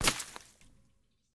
dirt01gr.ogg